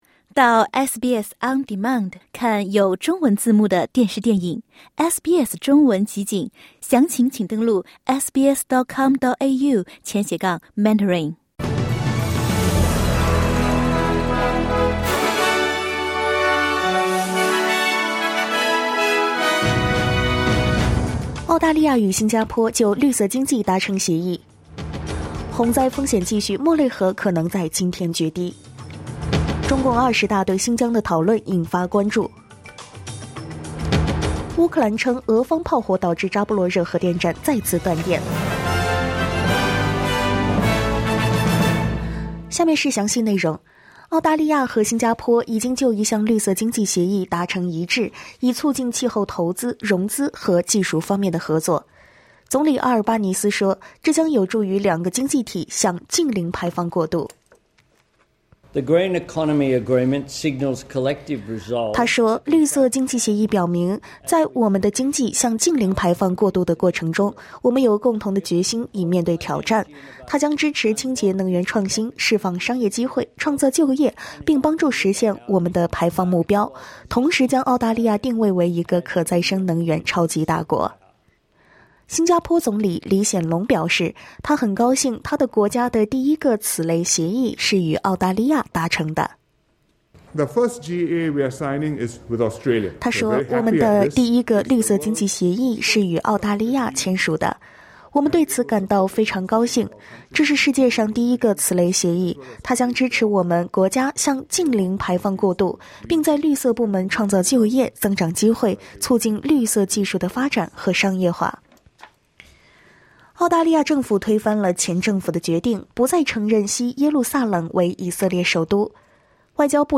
SBS早新闻（10月19日）